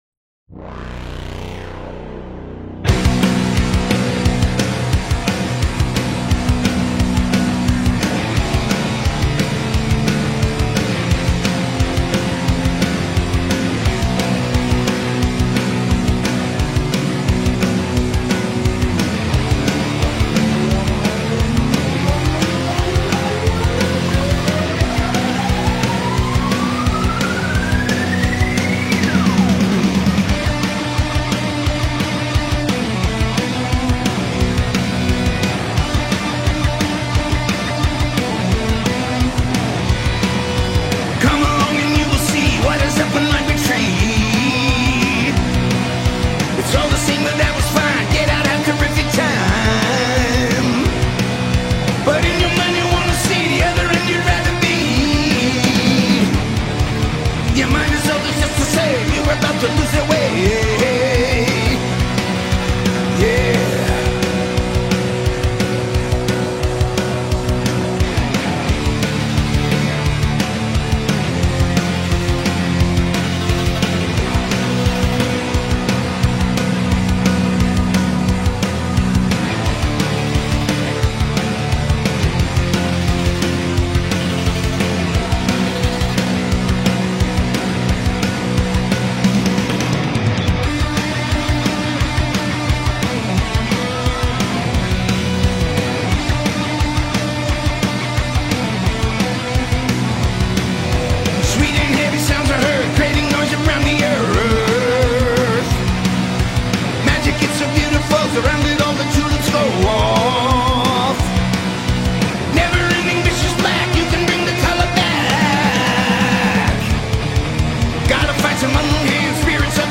There’s a grit here that feels intentional, not accidental.